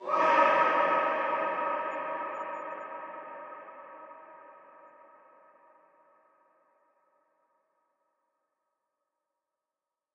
Reverbed Vocal » screaming 4
描述：Human scream processed through reverb unit.
标签： scream human vocal
声道立体声